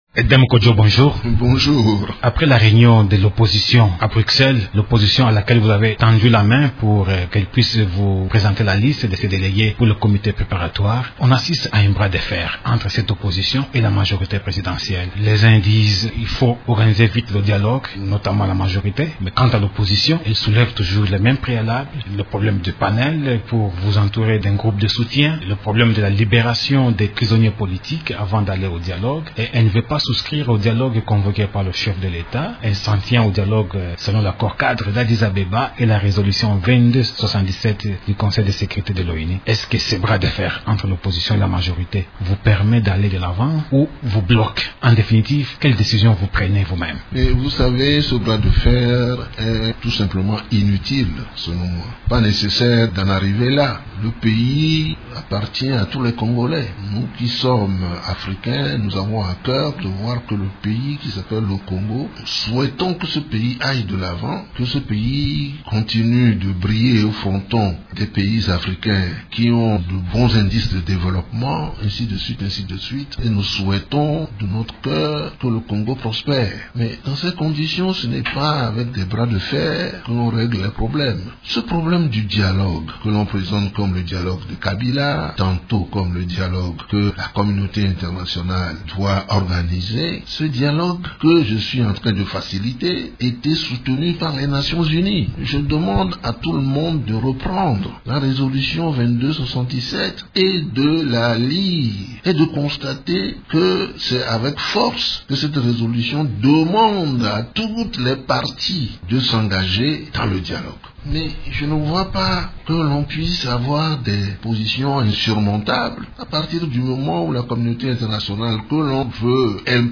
Invité de Radio Okapi mercredi 15 juin, l’ex-secrétaire général de l’Organisation de l’unité africaine (OUA) a appelé les Congolais à harmoniser leurs points de vue.